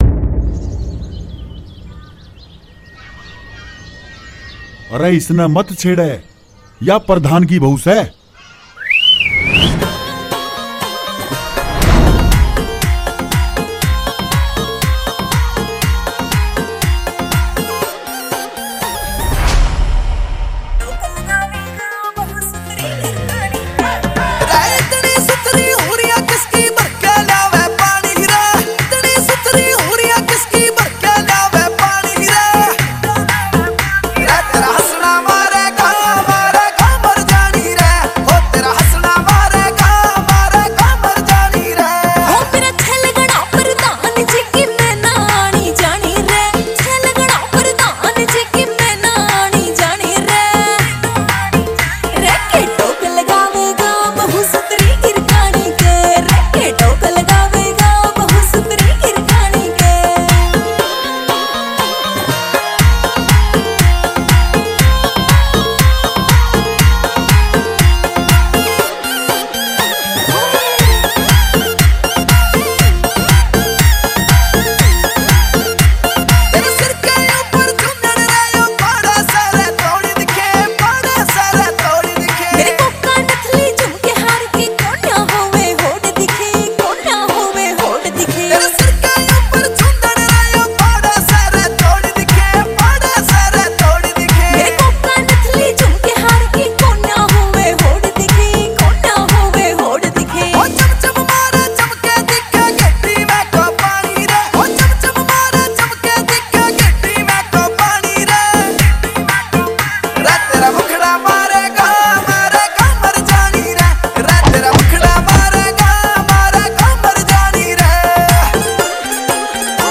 New Haryanvi DJ Songs 2024